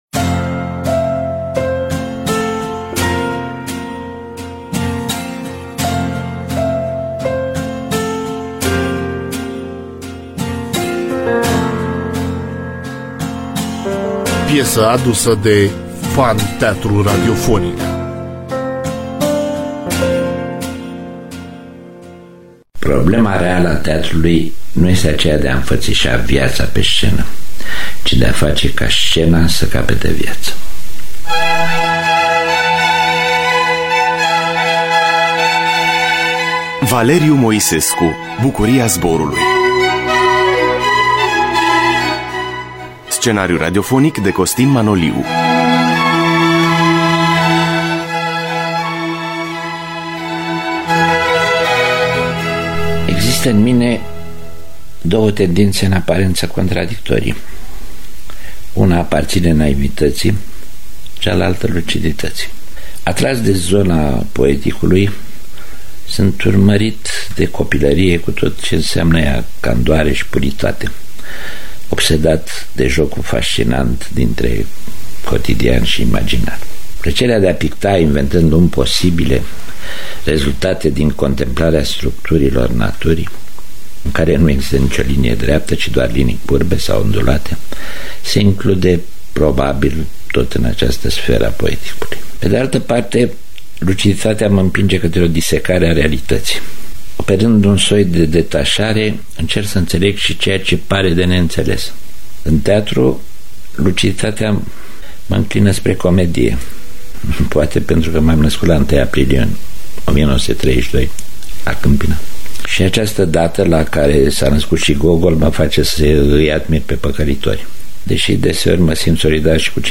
Scenariu radiofonic
Cu participarea extraordinară a regizorului Valeriu Moisescu.